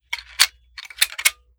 7Mag Bolt Action Rifle - Loading Rounds 001.wav